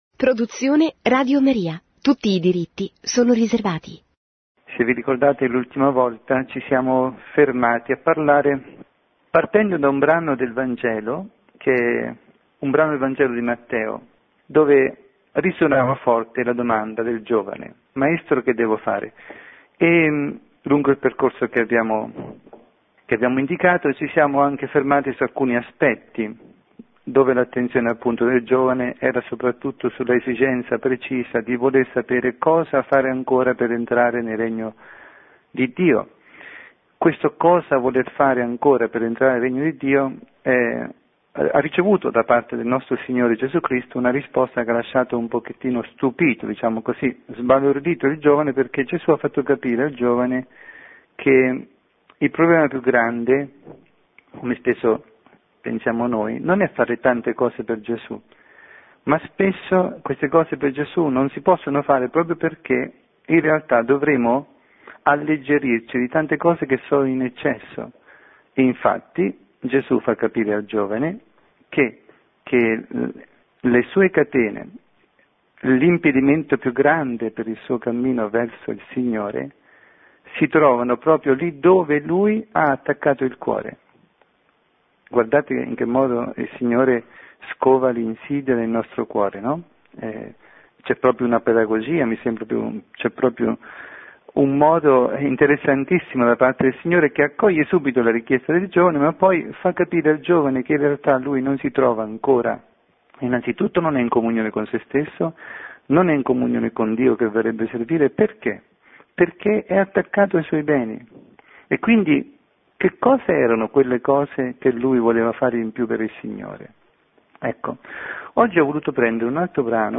Catechesi
trasmessa in diretta su RadioMaria